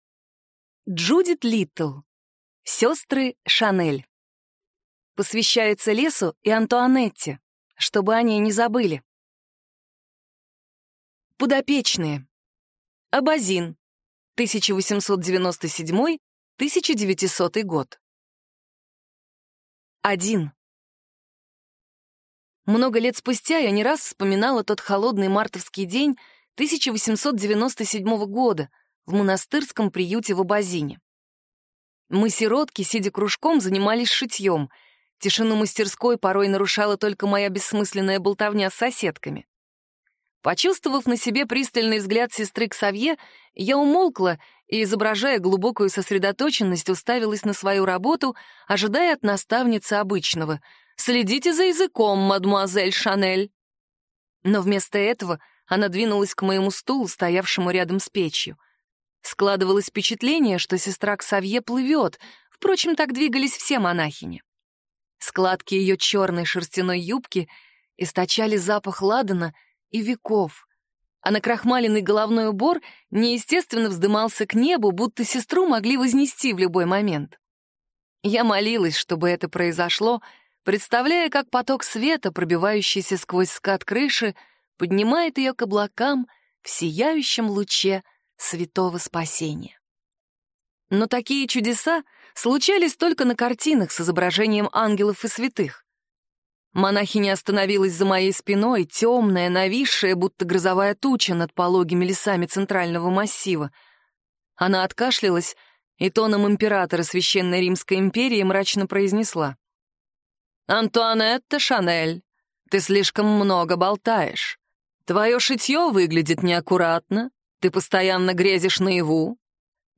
Аудиокнига Сестры Шанель | Библиотека аудиокниг